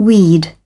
Transcription and pronunciation of the word "weed" in British and American variants.